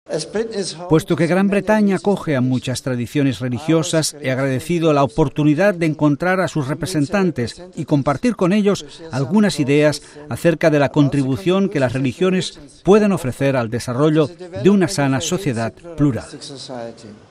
Domingo, 19 sep (RV).- Durante la ceremonia de despedida, que tuvo lugar en el aeropuerto internacional de Birmingham, el Papa, tras el discurso del primer ministro de Su Majestad, David Cameron, agradeció el intenso trabajo de preparación, tanto del Gobierno actual como del precedente, del servicio civil, de las autoridades locales y la policía, y de los numerosos voluntarios que pacientemente ayudaron a preparar los eventos de estos cuatro días.
DISCURSO DE DESPEDIDA